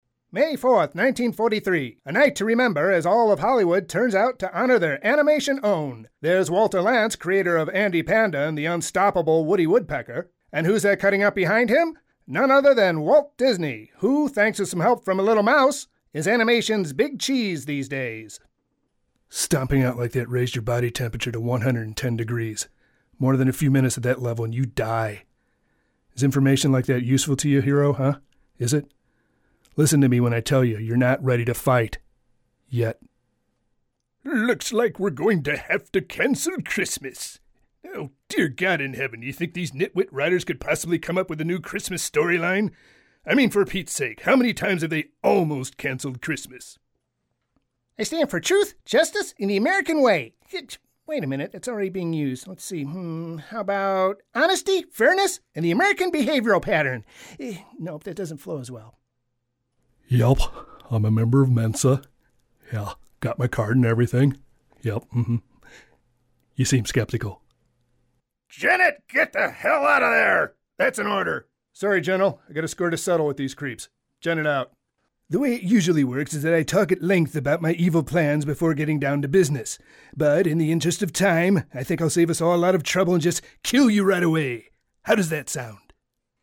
VO Demo Reels